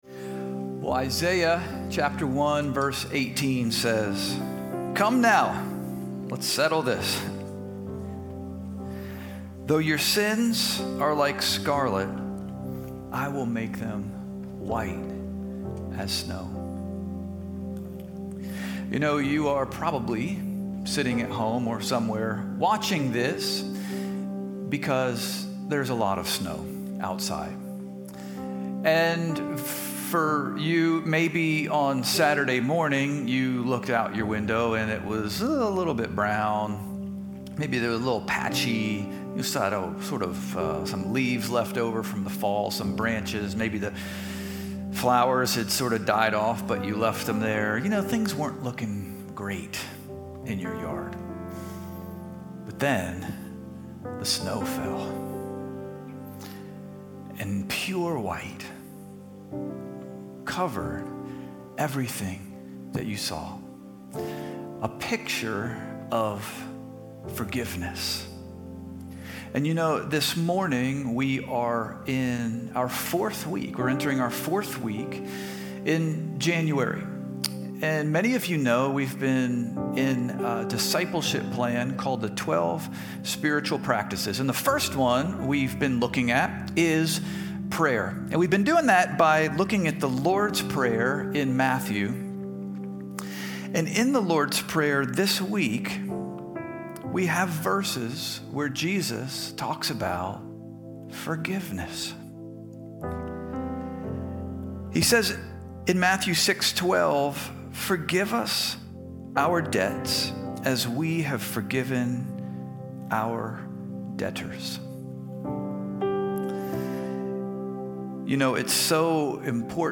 However, this provided an opportunity to prerecord a meaningful worship and prayer service.